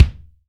Index of /kb6/Akai_MPC500/1. Kits/Funk Set
LA_KICK2.WAV